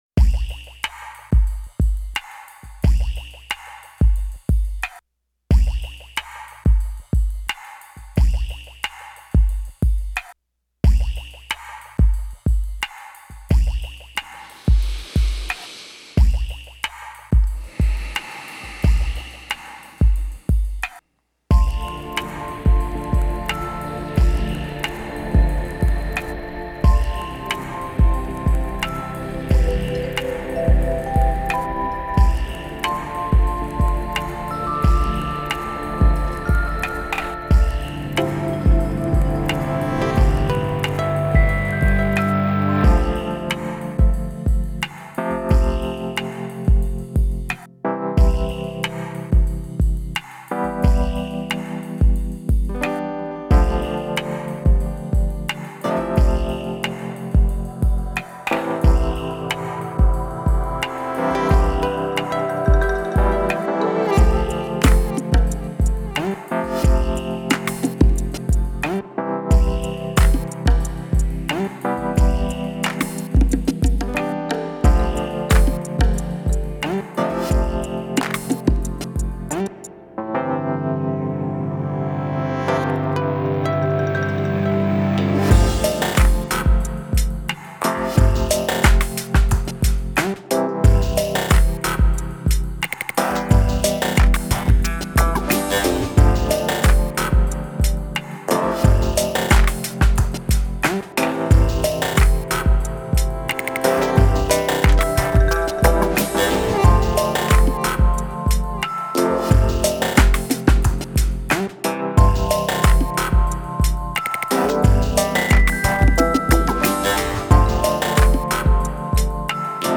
Genre: Downtempo, Chillout.